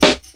• Good Hip-Hop Snare F# Key 03.wav
Royality free acoustic snare sound tuned to the F# note. Loudest frequency: 1980Hz
good-hip-hop-snare-f-sharp-key-03-EJT.wav